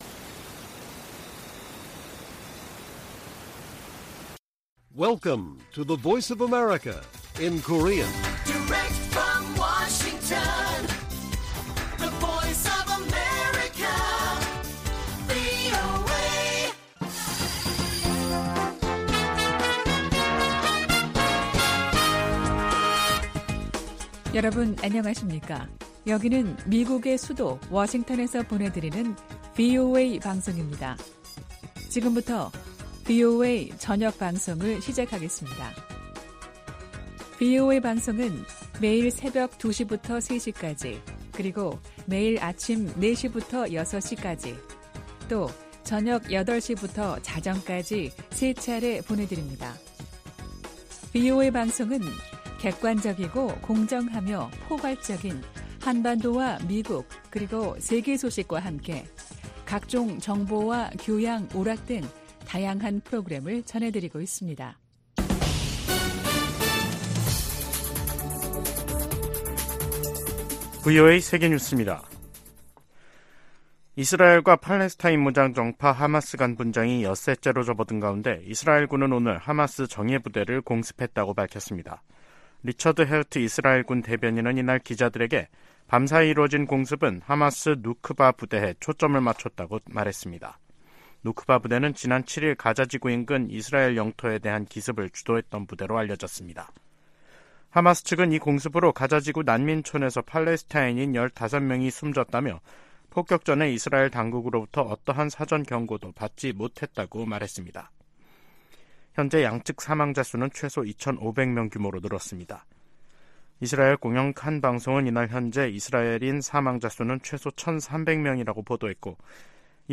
VOA 한국어 간판 뉴스 프로그램 '뉴스 투데이', 2023년 10월 12일 1부 방송입니다. 백악관이 이스라엘 지상군의 가자지구 투입에 대비해 민간인 대피 대책을 논의 중이라고 밝혔습니다. 한국을 방문한 미국 상원의원들이 윤석열 한국 대통령과 만나 대북 상호 방위에 대한 초당적 지지를 재확인했습니다. 9.19 남북군사합의가 팔레스타인 무장 정파 하마스식의 북한 기습 도발에 대한 감시·정찰 능력을 제한한다고 미국 전문가들이 지적했습니다.